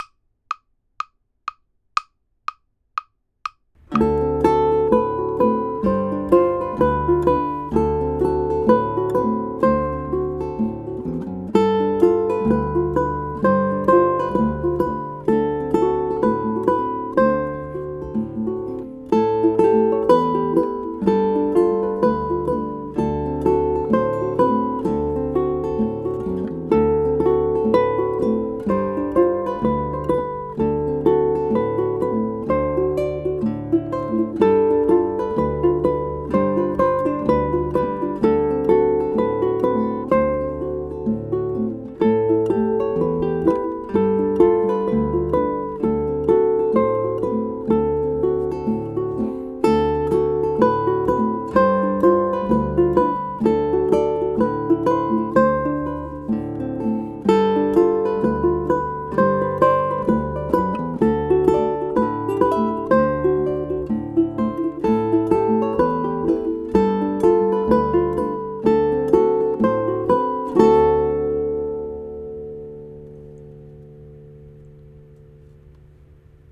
blues guitar icon ʻUkulele Blues
'ukulele
There are three chords in the ʻUkulele Blues: D7, G7 and A7.
Ukulele_Blues_(full_mix).mp3